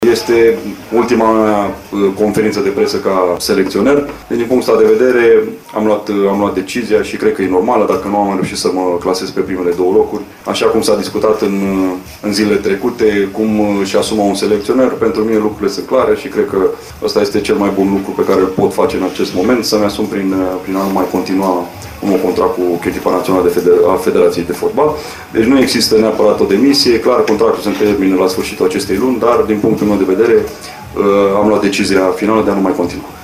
La conferința de presă din această seară, de la finele întâlnirii din Liechtenstein, selecționerul Mirel Rădoi a anunțat că nu mai continuă la echipa națională: